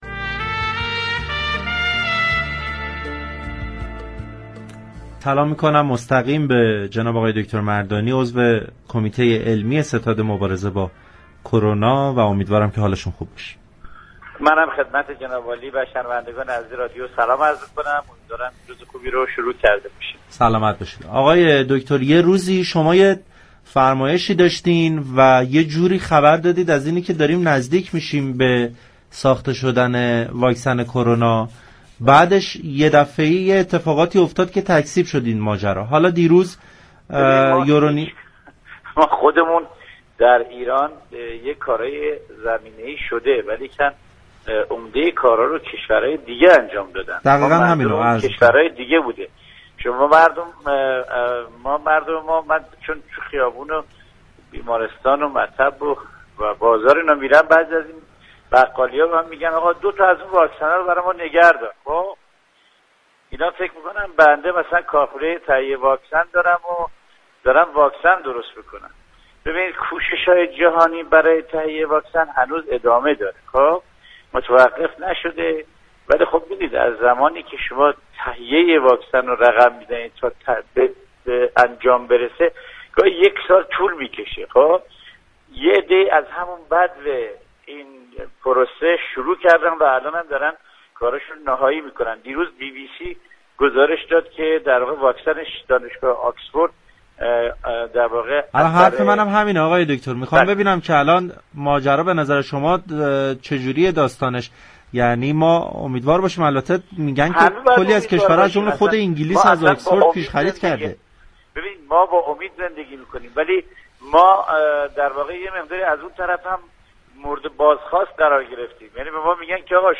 در گفتگو با برنامه پارك شهر